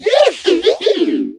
mech_mike_ulti_vo_01.ogg